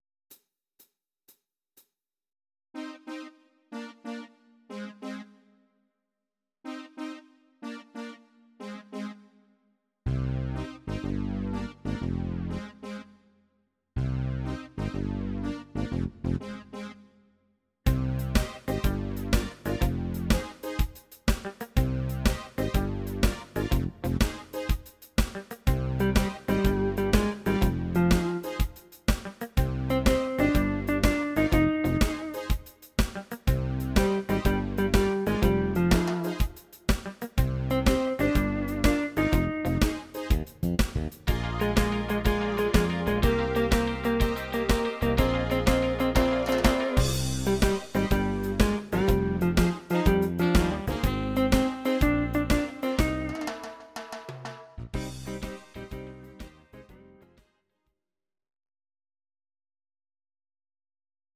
Audio Recordings based on Midi-files
Pop, Dutch, 1980s